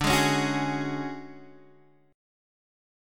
D Major 7th Flat 5th